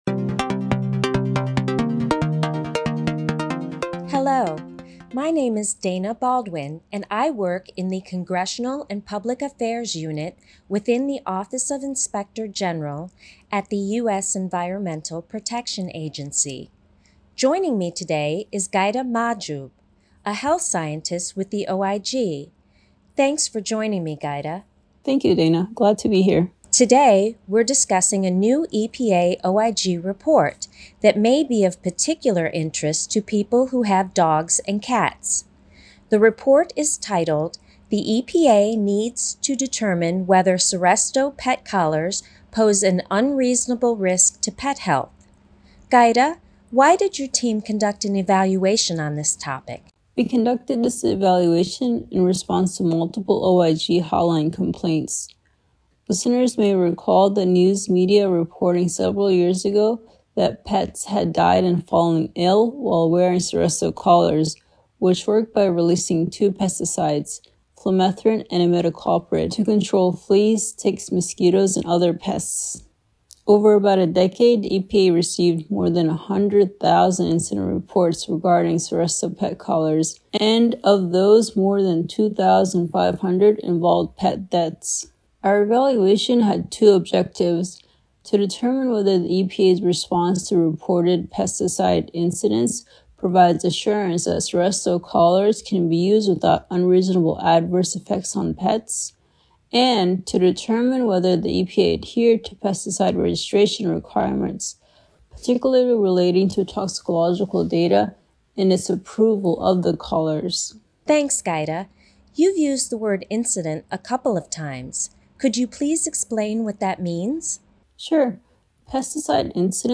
More Podcasts: Listen to our staff talk about their latest audit reports, investigative functions and other initiatives.